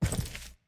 Footstep_Concrete 02.wav